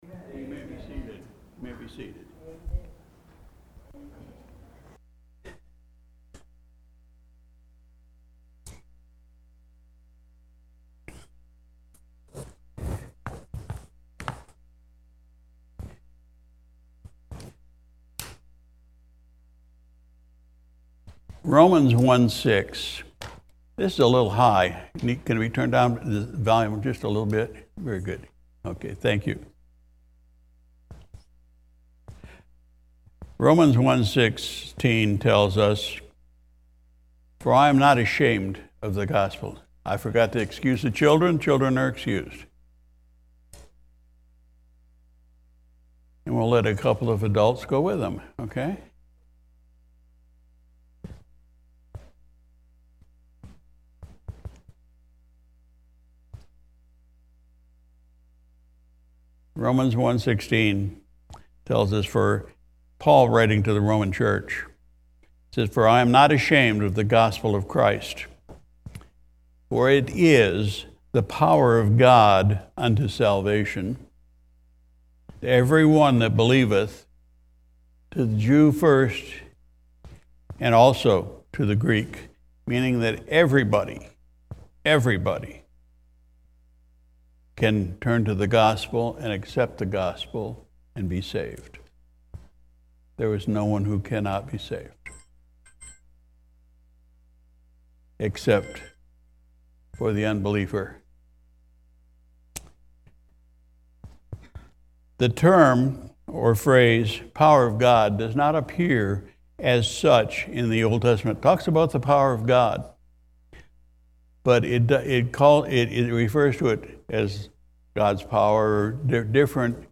Sunday Morning Service